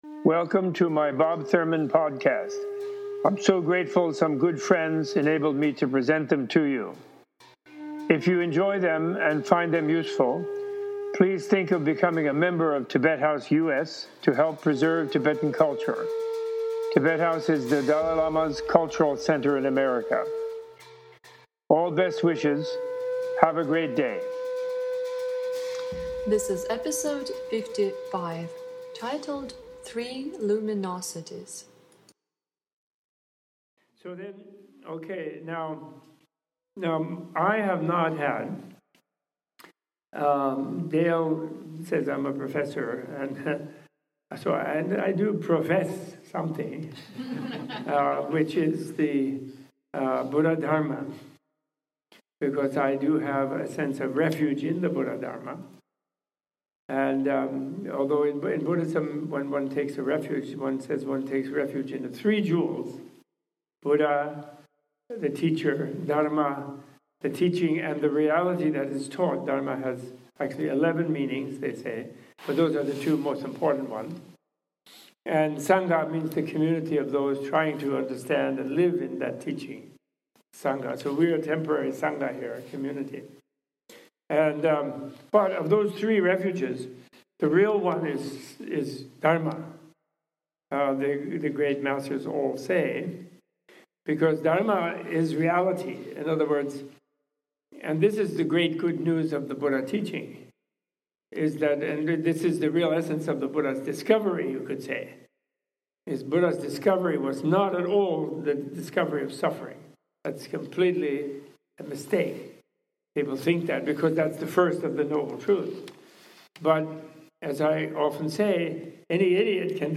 Excerpted from a lecture given on November 29, 2015 at Menla Retreat Center